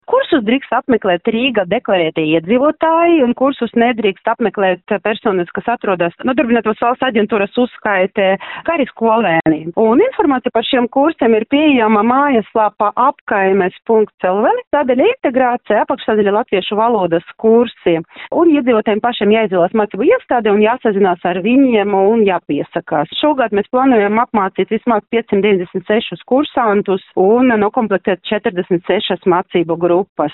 intervijā Skonto mediju grupai